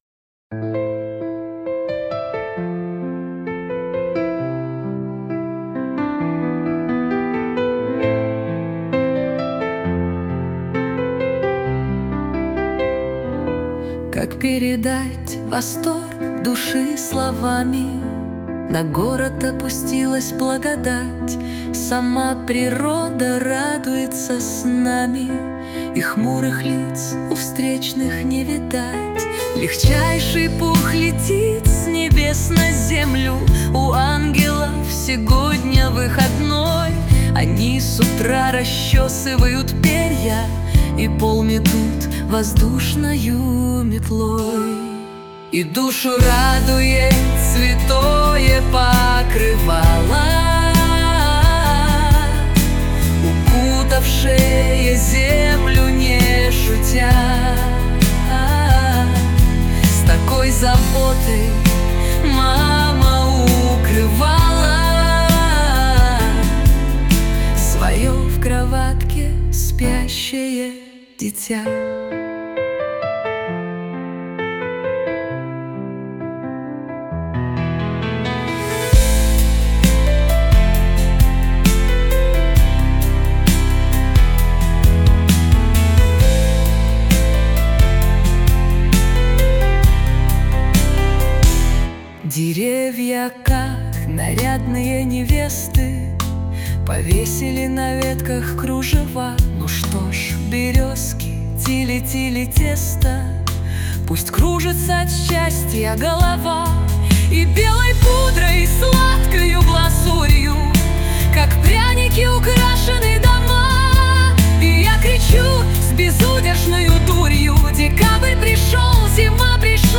• Аранжировка: Ai
• Жанр: Поп